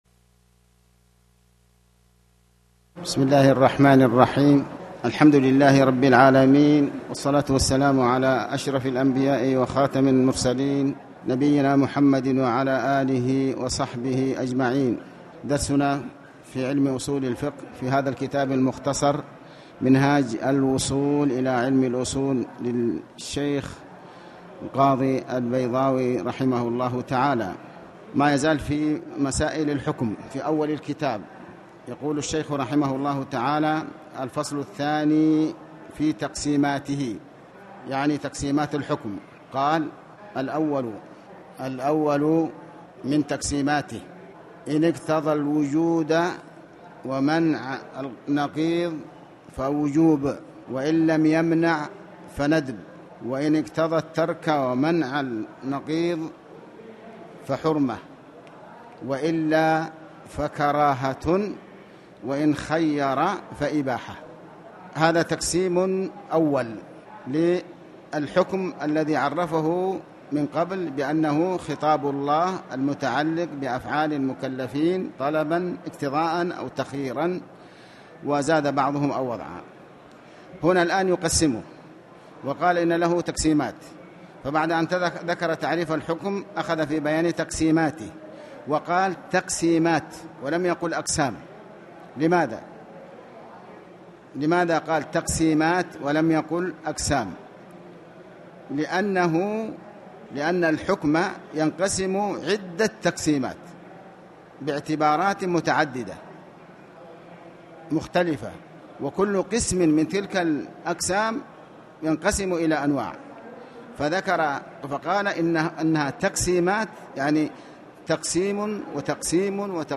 تاريخ النشر ٥ صفر ١٤٣٩ هـ المكان: المسجد الحرام الشيخ